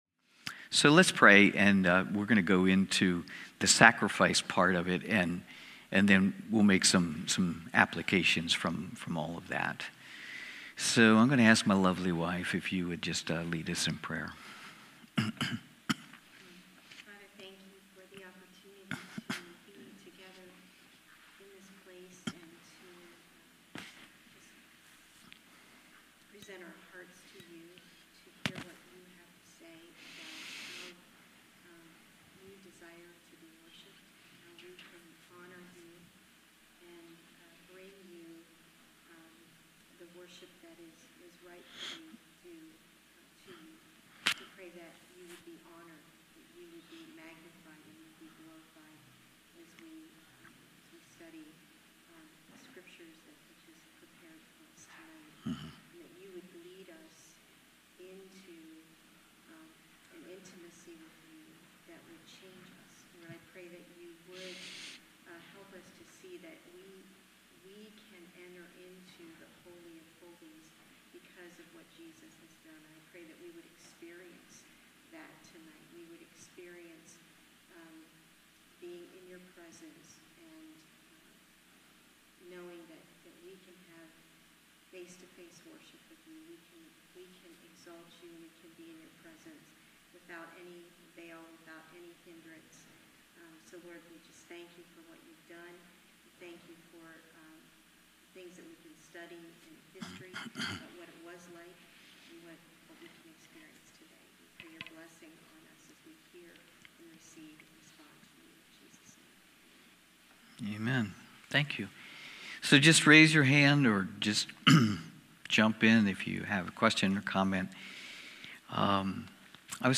Wednesday evening Bible study.